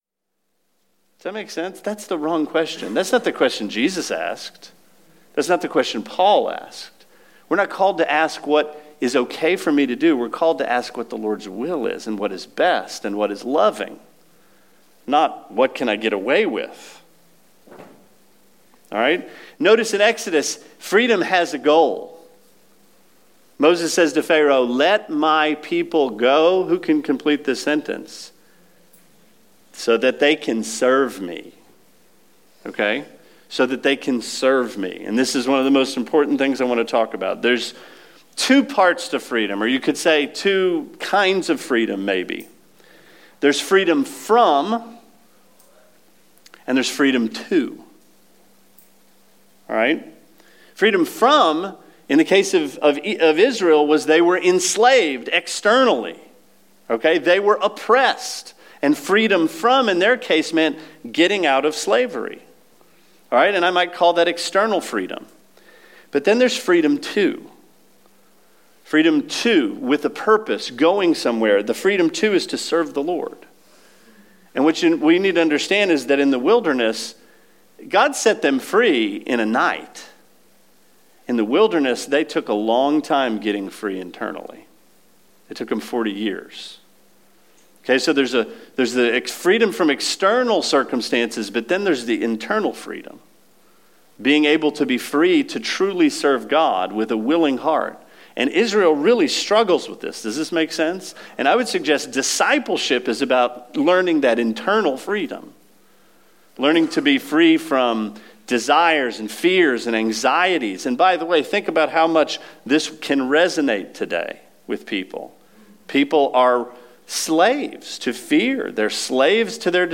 Sermon 06/07: Exodus is about Freedom